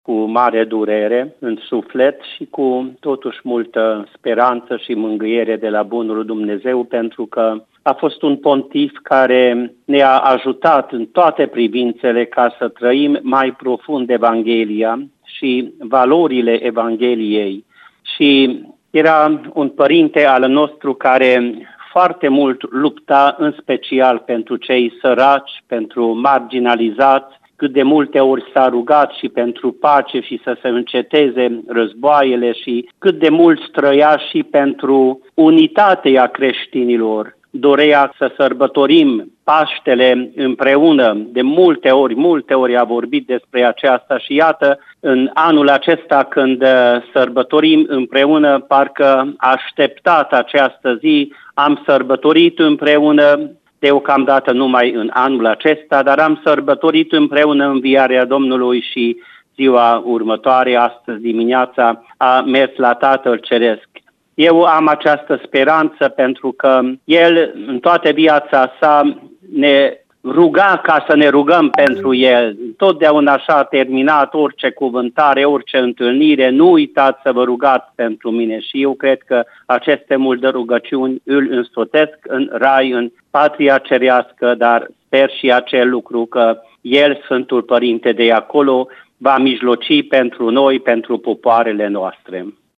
Excelența Sa Iosif Csaba Pál, episcopul diecezan al Timișoarei, a transmis un mesaj emoționant, în care a subliniat impactul profund pe care pontiful l-a avut asupra Bisericii și asupra vieților oamenilor.